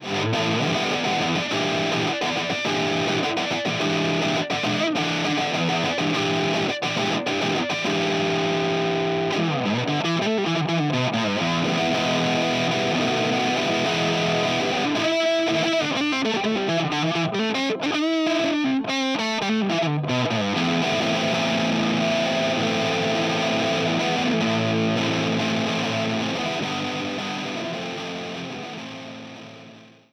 エレクトリック・ギター
ドライブ・サウンド
electricG_distortion.wav